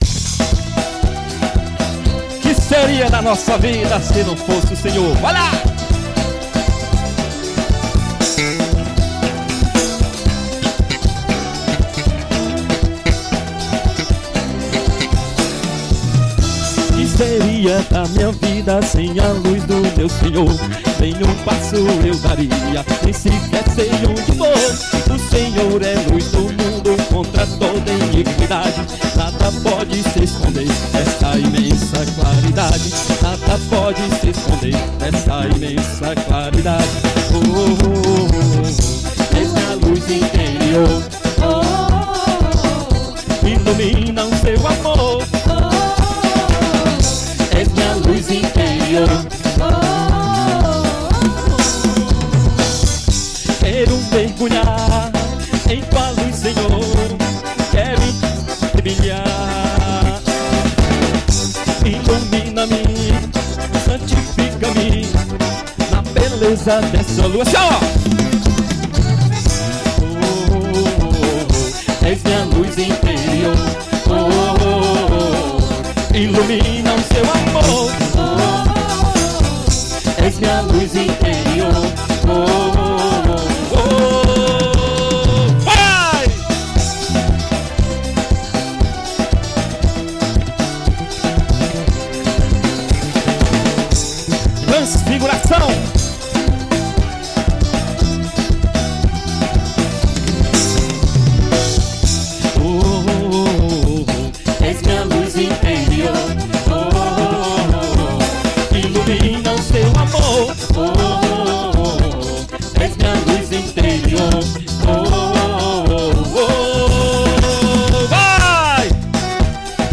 FORRÓ CATÓLICO.